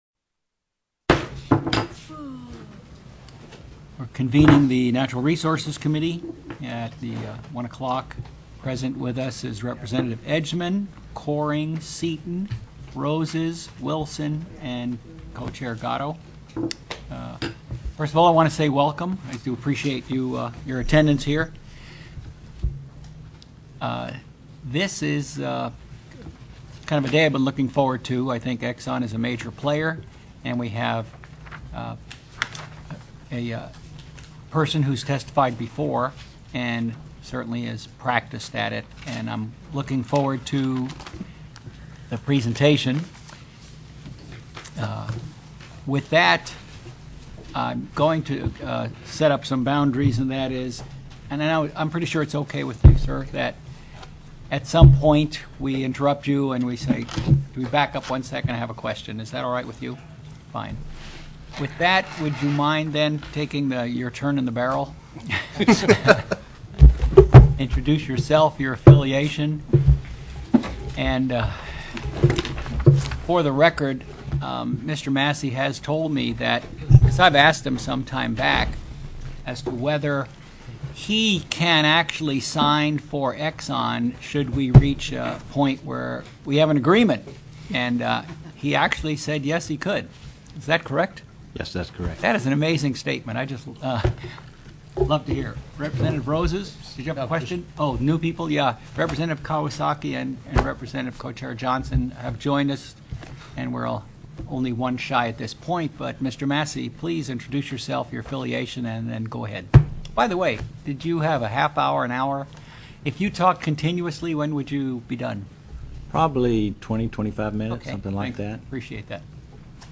04/12/2007 01:00 PM House RESOURCES